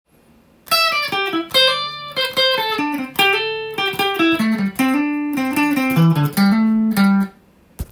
エレキギターで弾ける【歌うAマイナーペンタトニックスケール】シーケンスパターン【オリジナルtab譜】つくってみました
【歌うAマイナーペンタトニックスケール】シーケンスパターン